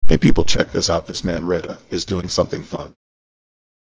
voice-cloning-AI - Voice cloning AI (deepfake for voice). Using cloned voice from only 5-10 seconds of targeted voice.